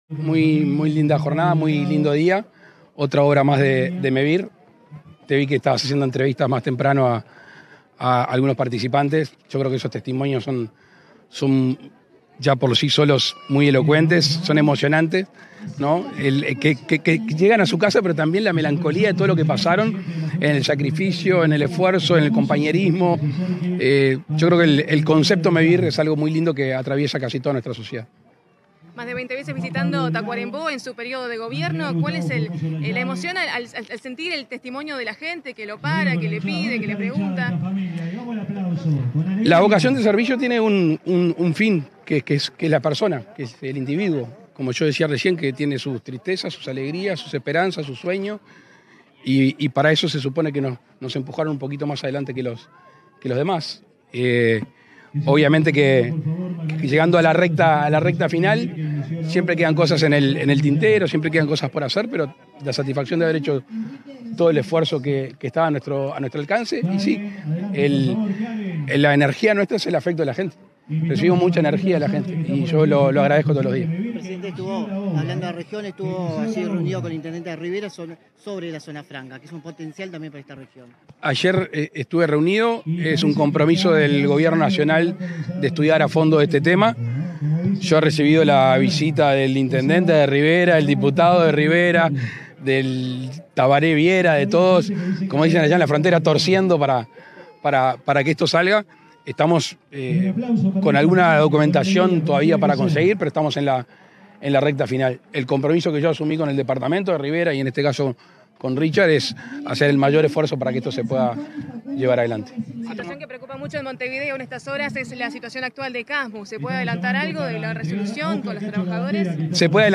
Declaraciones del presidente de la República Luis Lacalle Pou
Declaraciones del presidente de la República Luis Lacalle Pou 26/07/2024 Compartir Facebook X Copiar enlace WhatsApp LinkedIn Tras la entrega de 47 viviendas sustentables de Mevir construidas en madera, en el departamento de Tacuarembó, este 26 de julio, el presidente de la República, Luis Lacalle Pou, dialogó con la prensa.